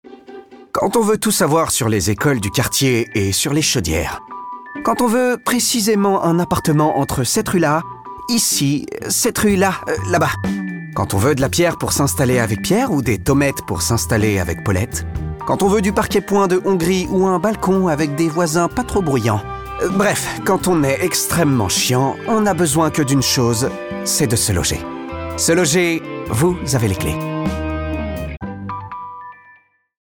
Pub Se Loger